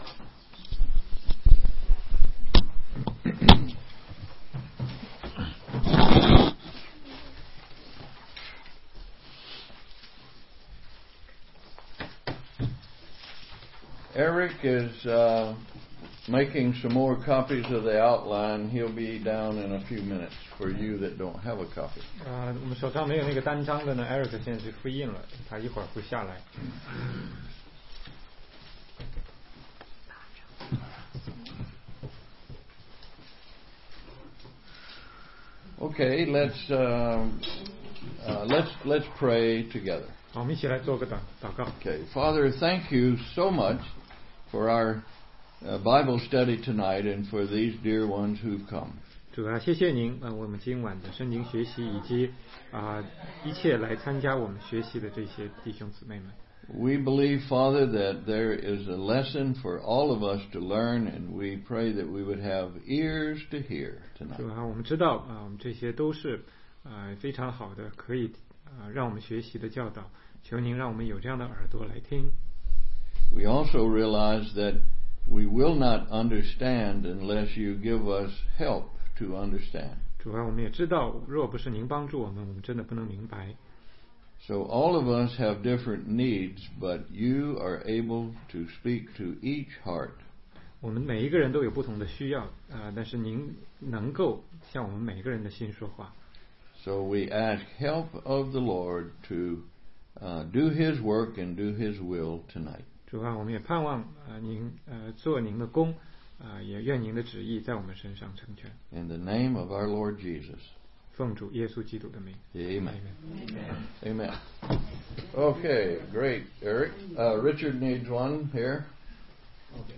16街讲道录音 - 好土